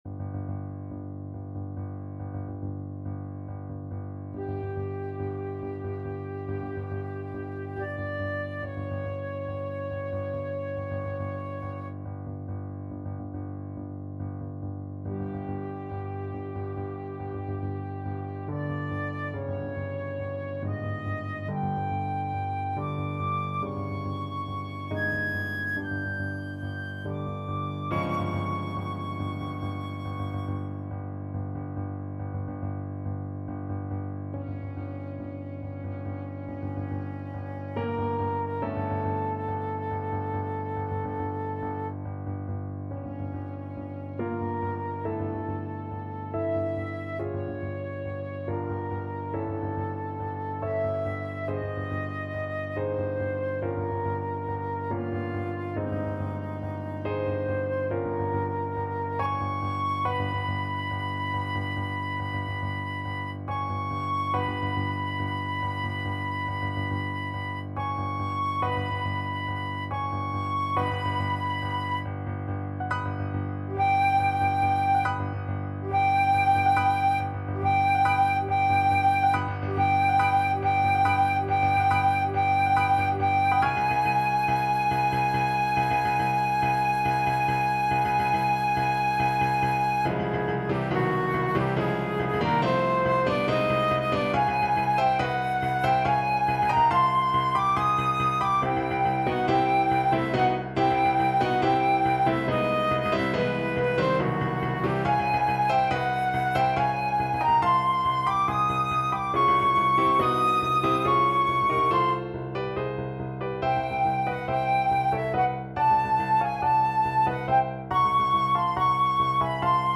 Classical Holst, Gustav Mars from The Planets Flute version
5/4 (View more 5/4 Music)
A minor (Sounding Pitch) (View more A minor Music for Flute )
Allegro = 140 (View more music marked Allegro)
Classical (View more Classical Flute Music)